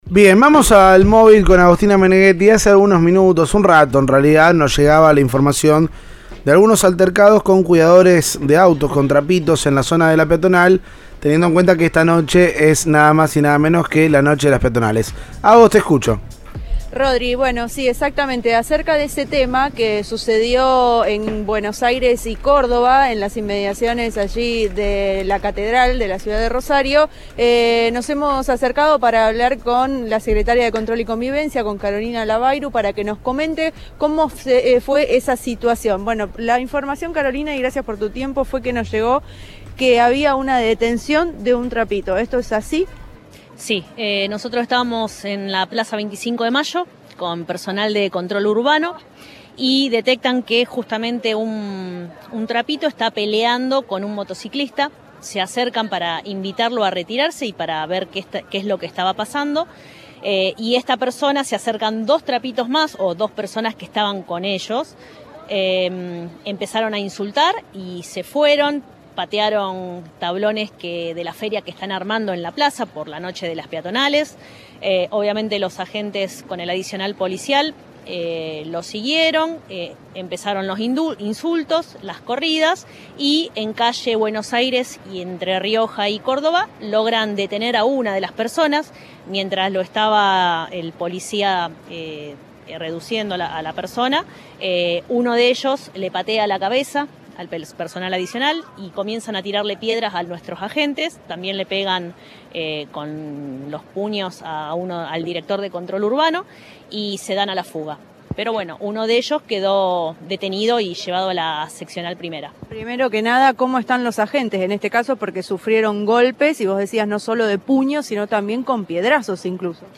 La secretaria de Control, Carolina Labayrú, habló en Cadena 3.
Informe